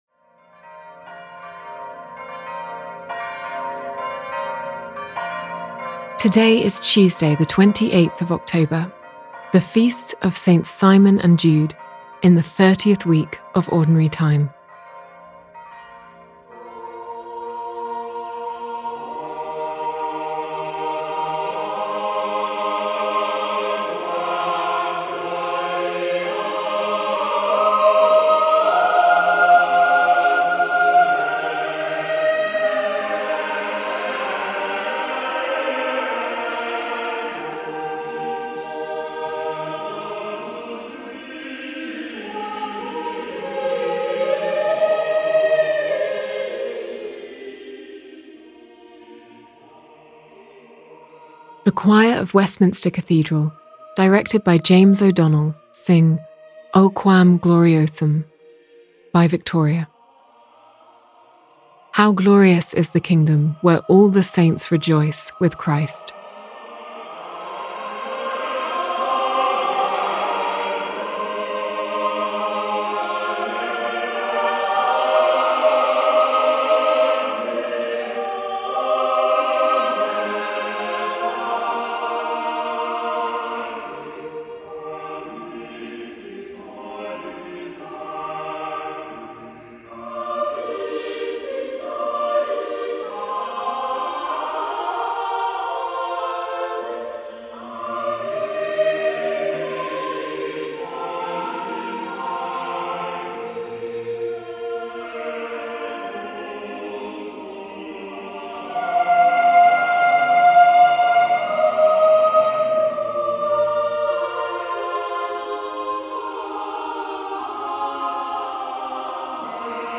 The Choir of Westminster Cathedral, directed by James O’Donnell, sing O quam gloriosum by Victoria: ‘How glorious is the kingdom, where all the saints rejoice with Christ.’